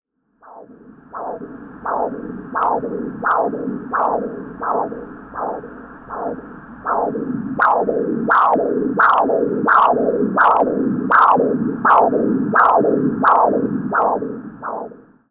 Коллекция включает разные варианты записей, от монотонных сигналов до динамичных изменений.
Звуки ультразвуковой сонографии